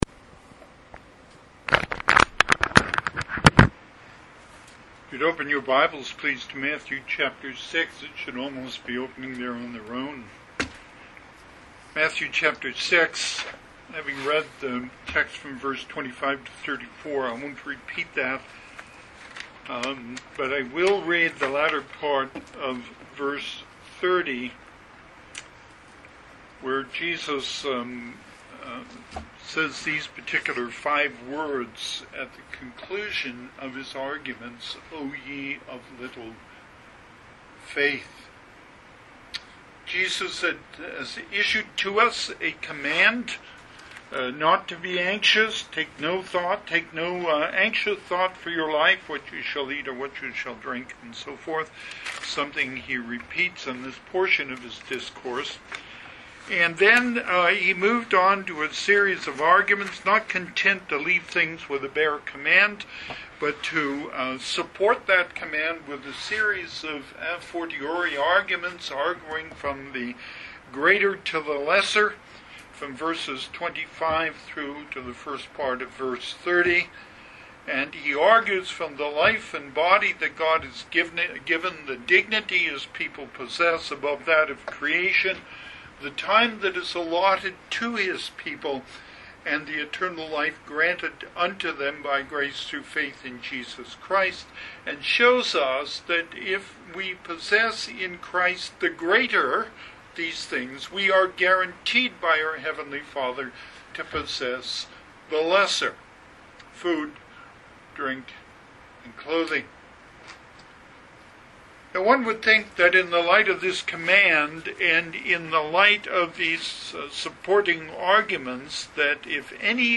Passage: Matthew 6: 30 Service Type: Sunday AM